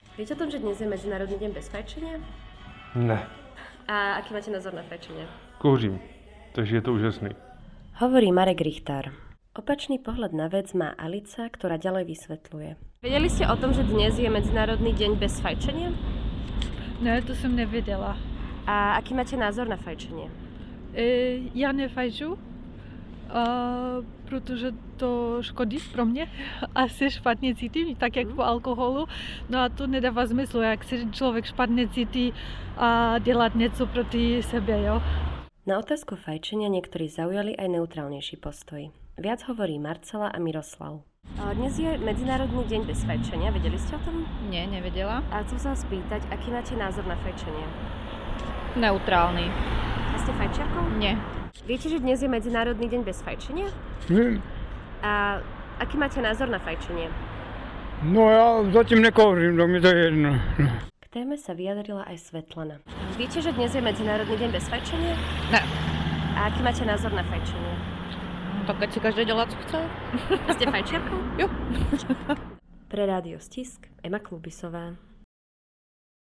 Anketa: Čo si Brňania myslia o fajčení?
Na otázku fajčenia som sa pýtala ľudí v uliciach Brna.